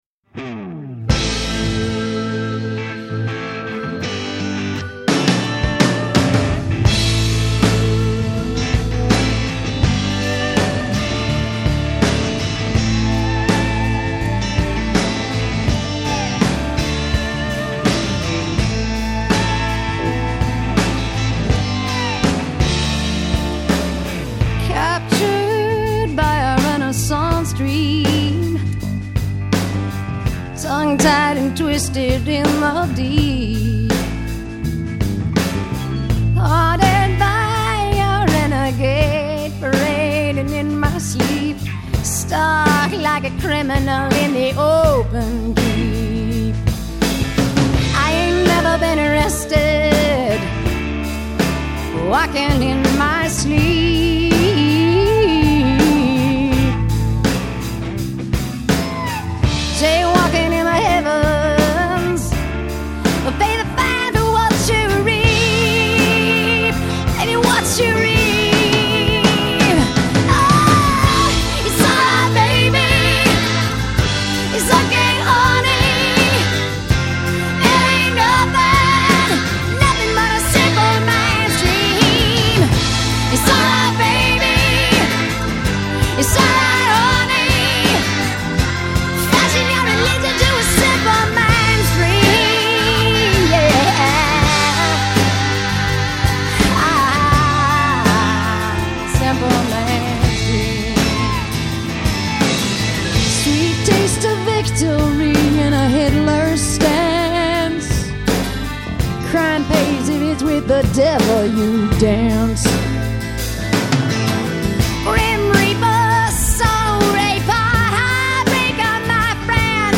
Жанр: rock